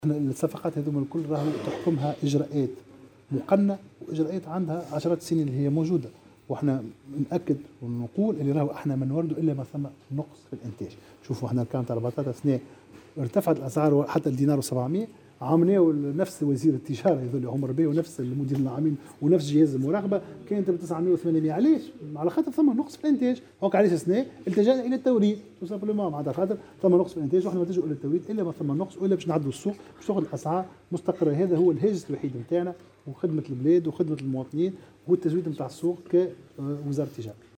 وأضاف خلال ندوة صحفية انعقدت اليوم بمقر الوزارة أنه لا يتم اللجوء إلى التوريد إلاّ في صورة وجود نقص في الإنتاج ولتعديل السوق.وتابع وزير التجارة : هاجسنا الوحيد هو تزويد السوق وتعديل الأسعار وخدمة المواطنين لا غير".